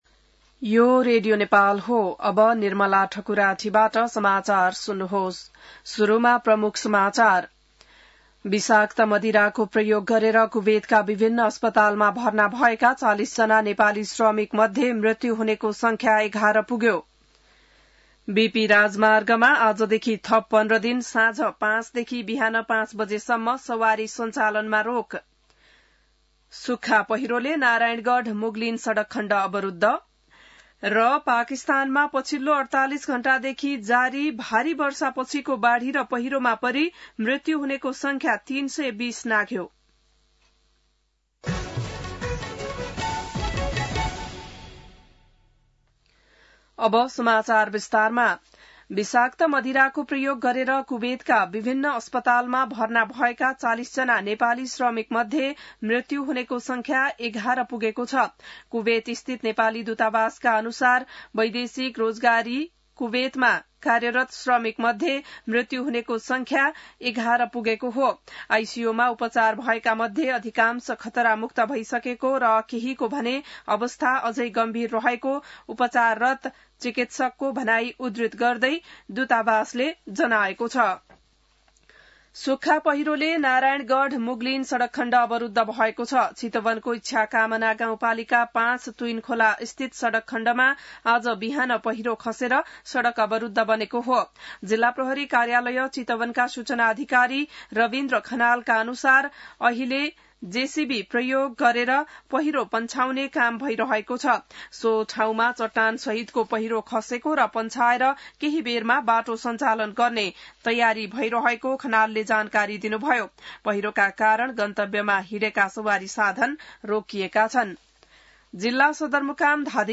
बिहान ९ बजेको नेपाली समाचार : १ भदौ , २०८२